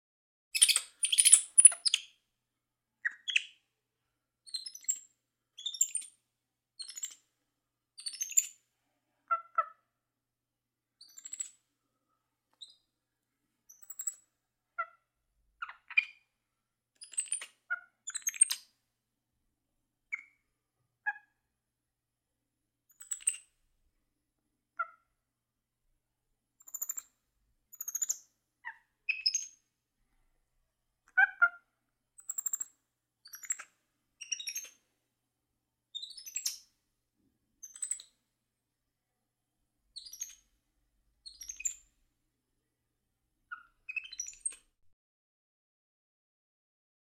Monkey, Capuchin Chatter. High Pitched Squeals Chirping Sound. Close Perspective